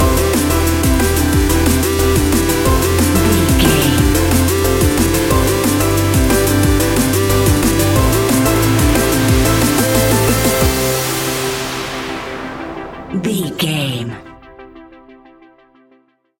Ionian/Major
Fast
groovy
uplifting
futuristic
driving
energetic
repetitive
drum machine
synthesiser
electronic
sub bass
synth leads
synth bass